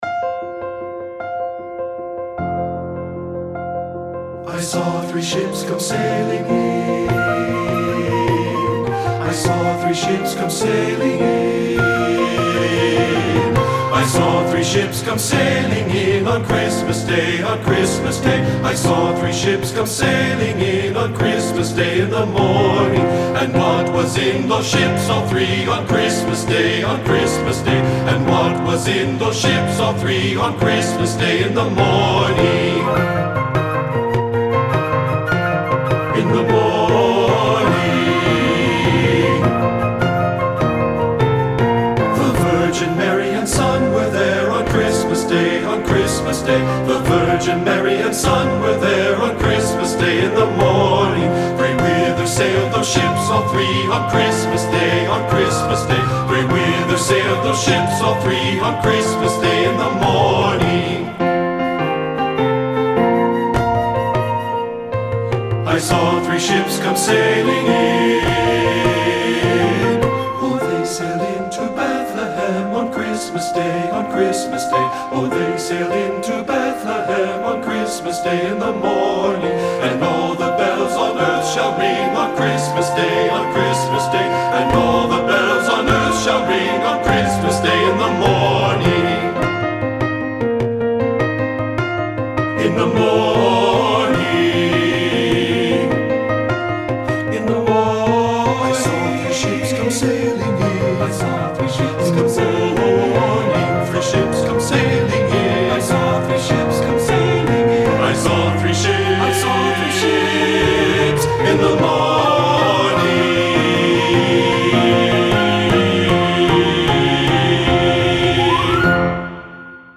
TB Voices with Piano
• Tenor
• Bass
• Flute (opt.)
• Frame Drum (opt.)
• Piano
Studio Recording
Jolly and jaunty, rhythmic and fun
Ensemble: Tenor-Bass Chorus
Accompanied: Accompanied Chorus